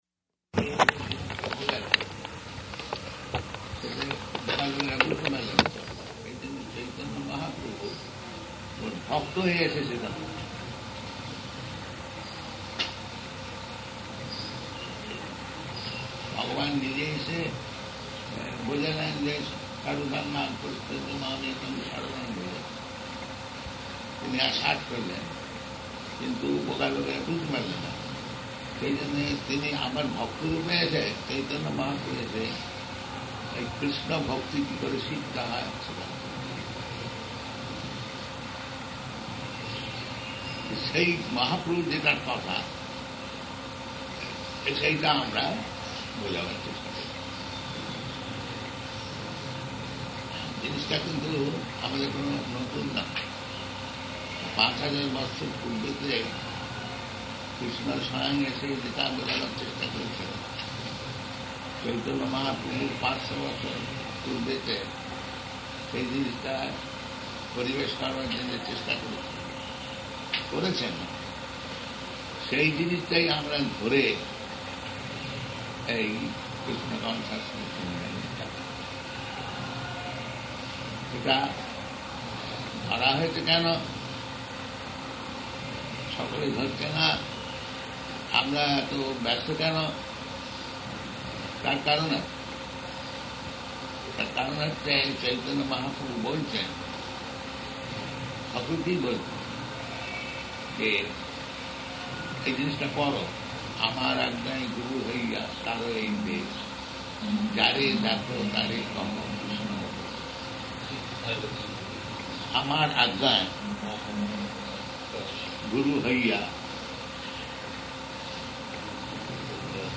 Interview
Interview --:-- --:-- Type: Interview Dated: February 26th 1977 Location: Māyāpur Audio file: 770226IV.MAY.mp3 [First 28 minutes of audio is talk between 2 interviewers and Prabhupada in Hindi, discussing, among other things, books produced in other languages. A few English words interjected.]